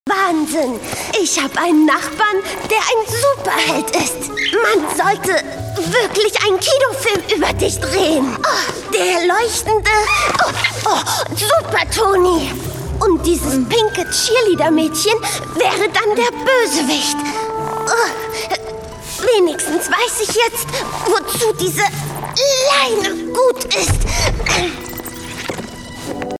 Werbung - Demoreel